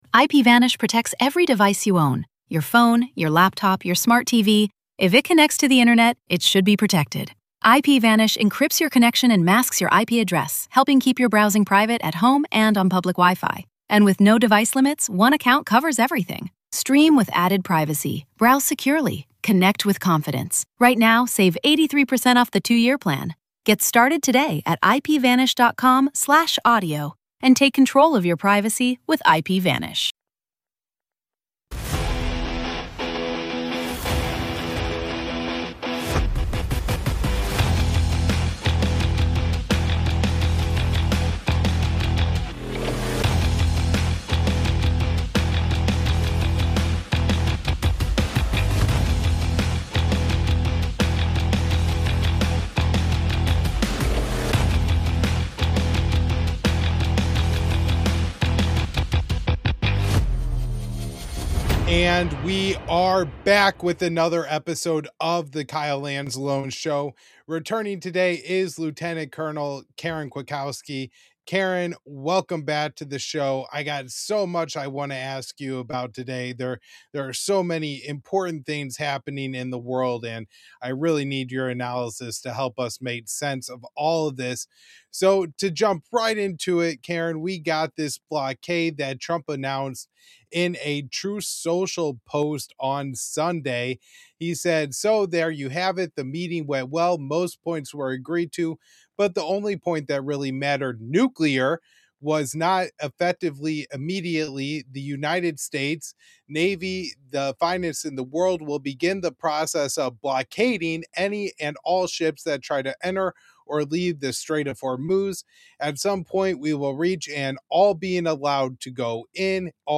We close with a blunt conversation about propaganda, legitimacy, and the strange politics of personality cult symbols, including Trump’s AI “Jesus” image and what it signals about power at home and credibility abroad.